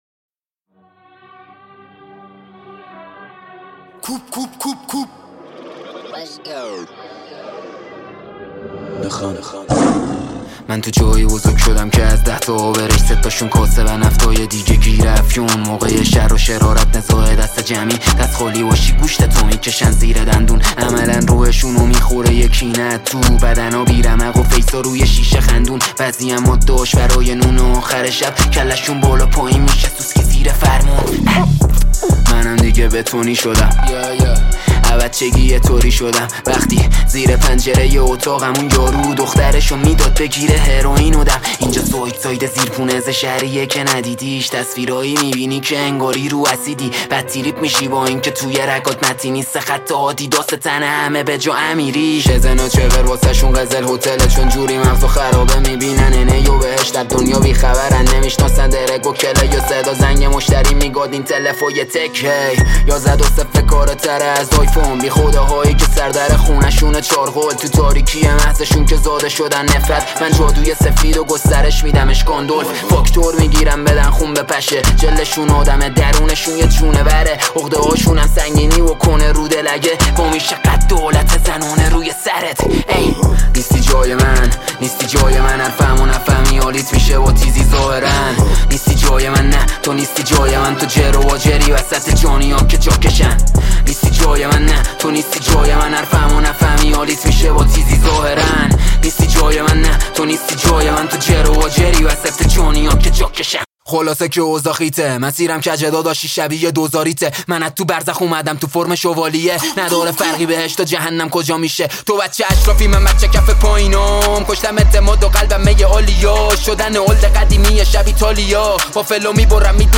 تک آهنگ